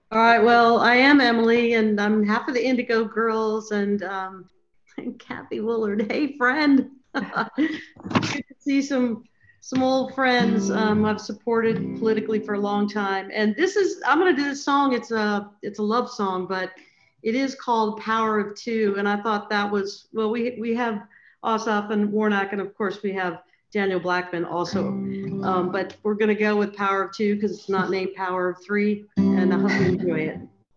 (audio captured from zoon meeting)
03. talking with the crowd (emily saliers) (0:30)